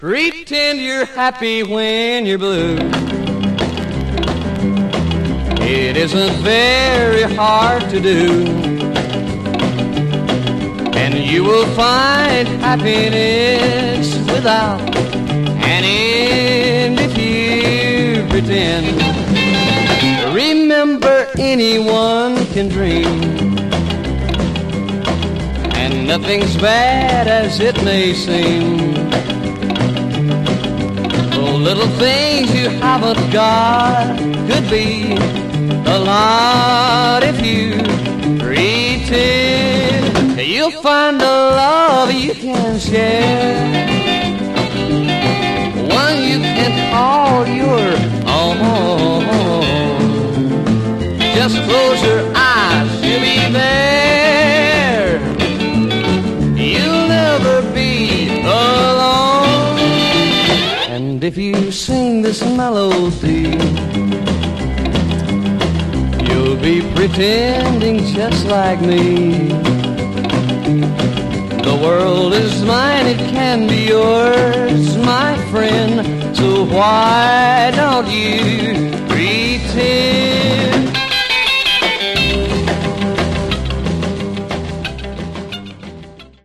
Genre: Rockabilly/Retro
two terrific uptempo Rockabilly sides